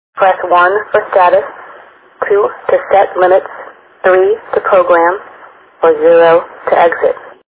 VM500-5 Voice Demonstration
Real Voice